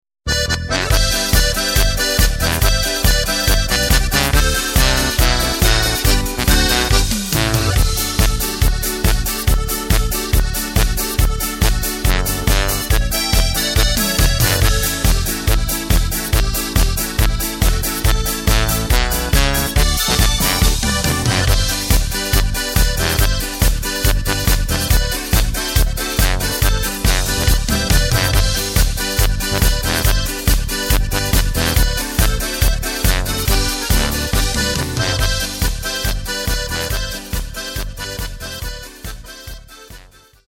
Tempo:         140.00
Tonart:            G
Flotte Polka aus dem Jahr 2005!
Playback mp3 Demo